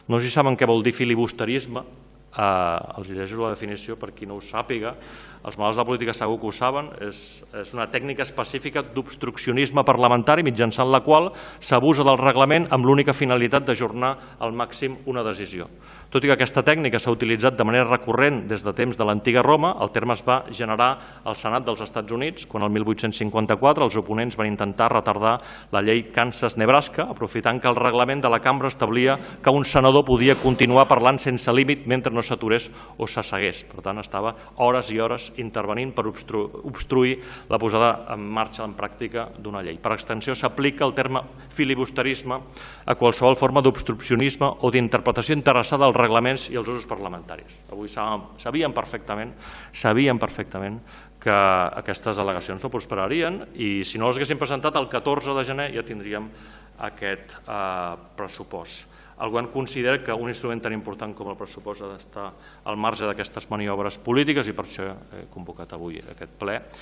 Salvatierra ha acusat els grups municipals que han presentat les al·legacions de “filibusterisme”, afegint que el pressupost hauria d’estar al marge d’aquestes maniobres polítiques: